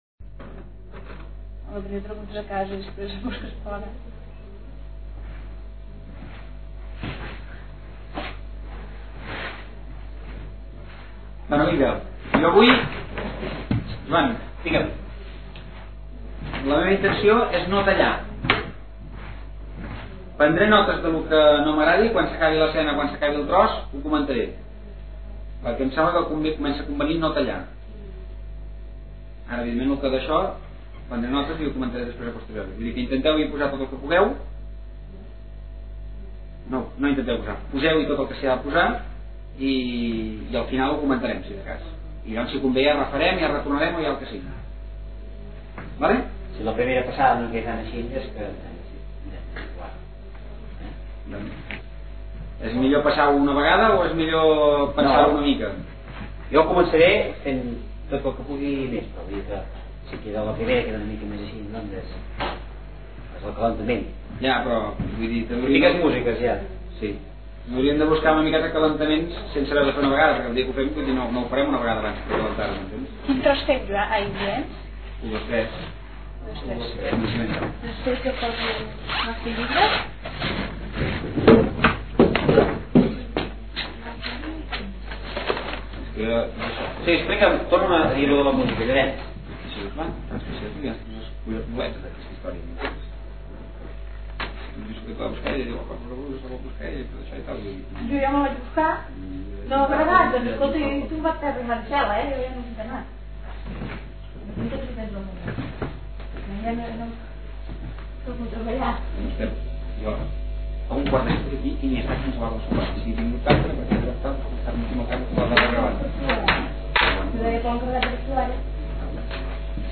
Corpus Oral de Registres (COR). CULT3. Assaig de teatre
Aquest document conté el text CULT3, un "assaig de teatre" que forma part del Corpus Oral de Registres (COR).